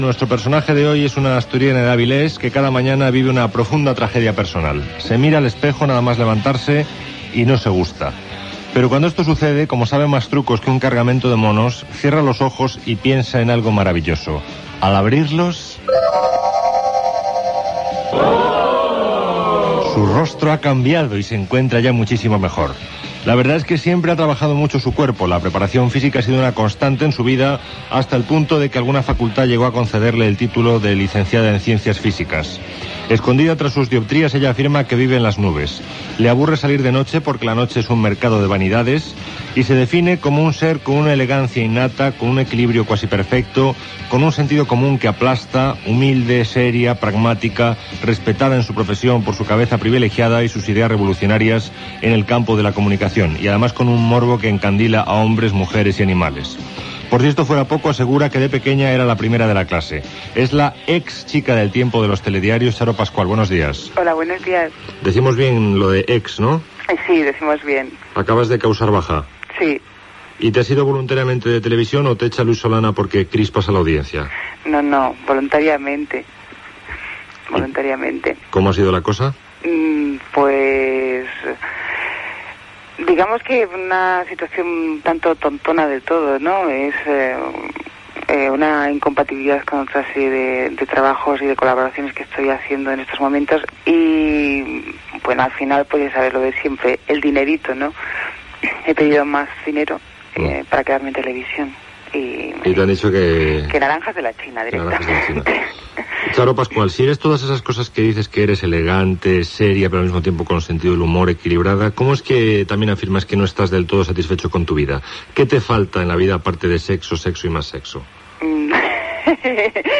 Entrevista a la física Charo Pascual que havia presentat la secció del temps dels Telediarios de TVE amb la intervenció d'un imitador del meteoròleg Mariano Medina. Paròdia de la sèrie de televisió Alf
Info-entreteniment